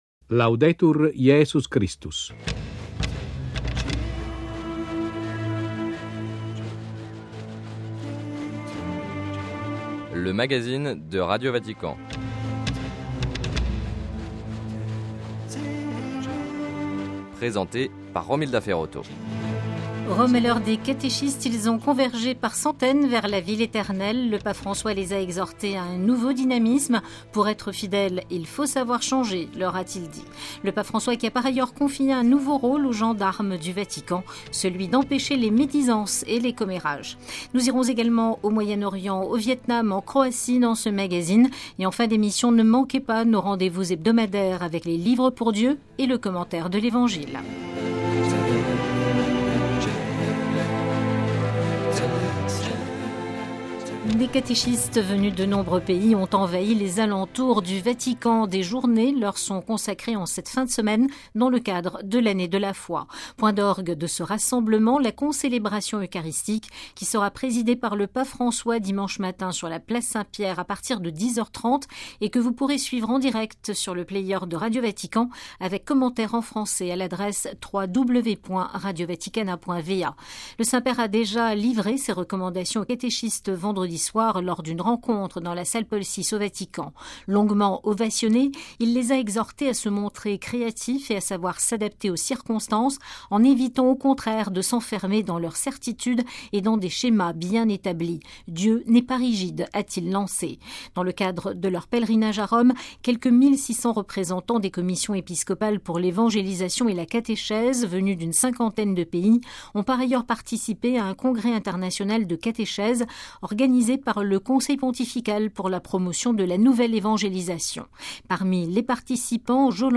- Réunion au Liban du Conseil des Patriarches catholiques d'Orient. - Entretien avec Mgr Paul-André Durocher, nouveau président de la Conférence des évêques du Canada.